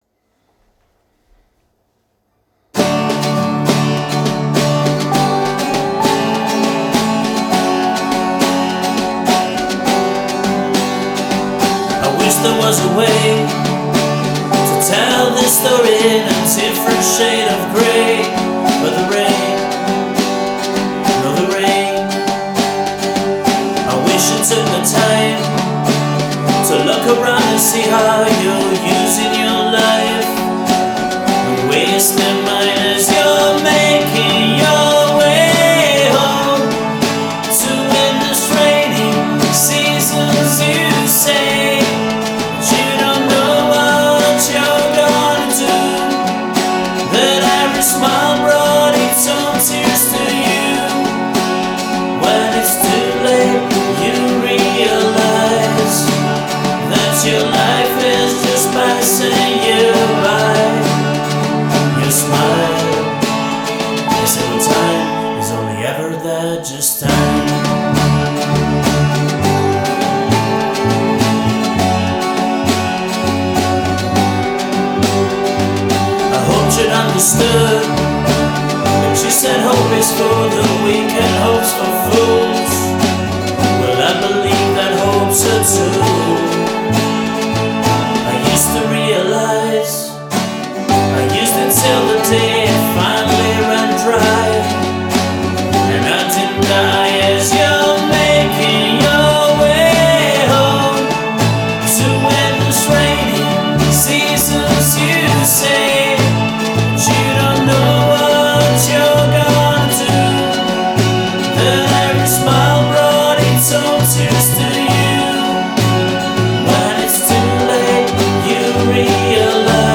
vocals, guitars, bass, keyboards, drums